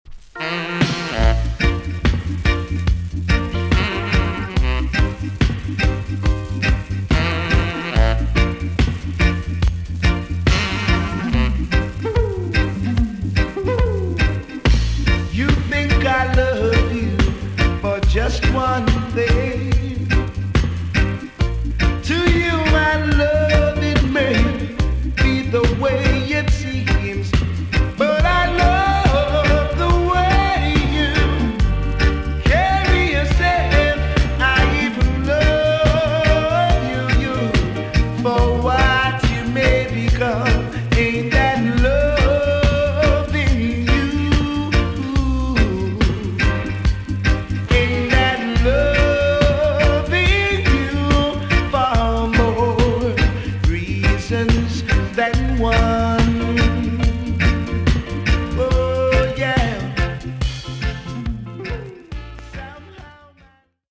TOP >DISCO45 >VINTAGE , OLDIES , REGGAE
EX-~VG+ 少し軽いヒスノイズがありますが良好です。